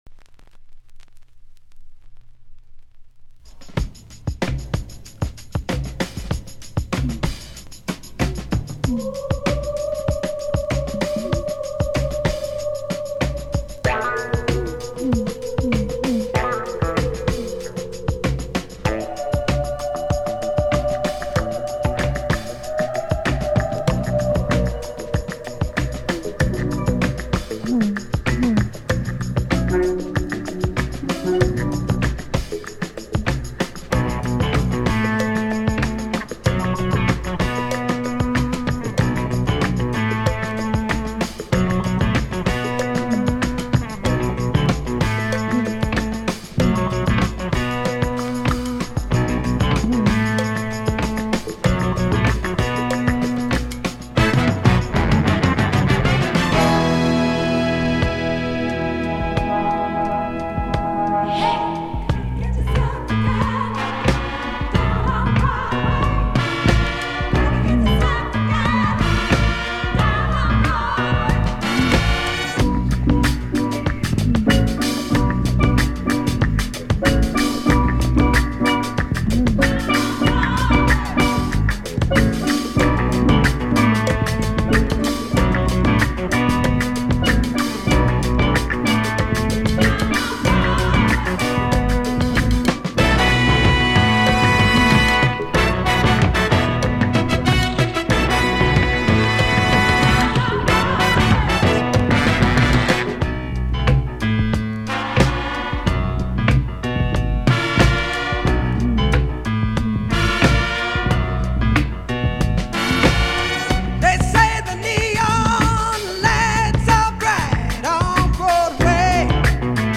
timbale
bongos